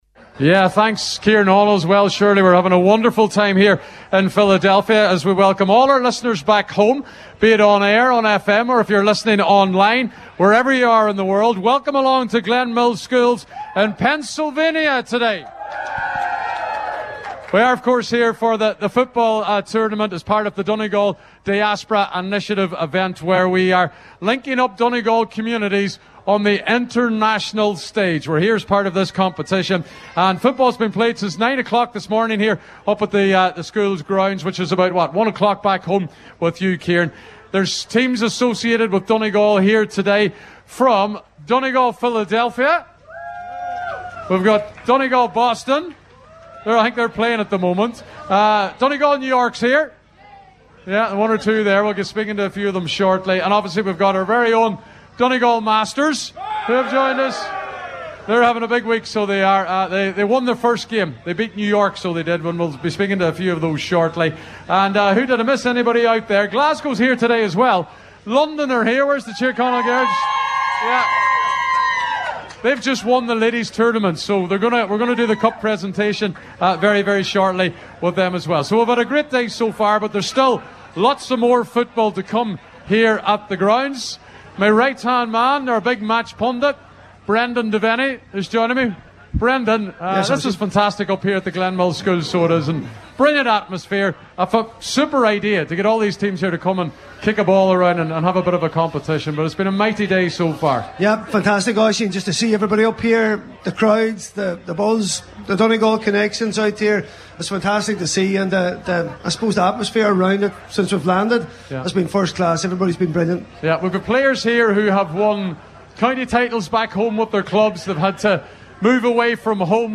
Highland Radio broadcast live from Philadelphia once again this afternoon as the Charlie McElwee Cup was taking place at Glen Hills School.